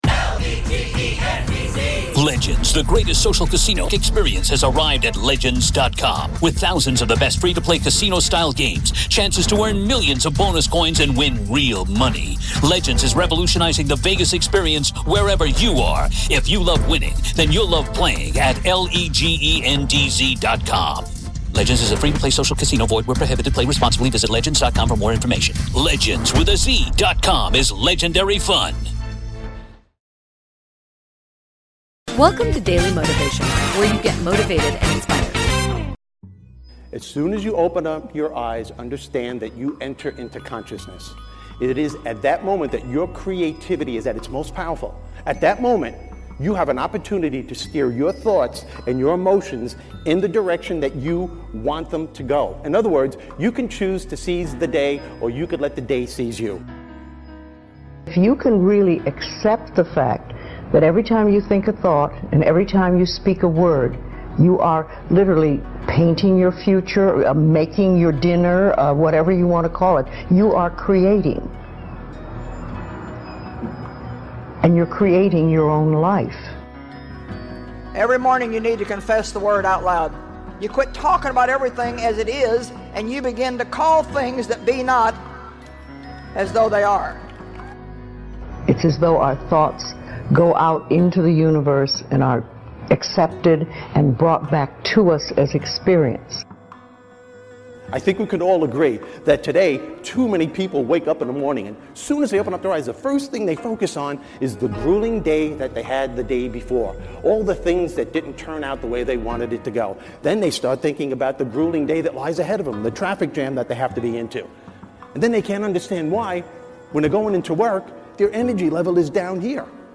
Speaker: Mel Robbins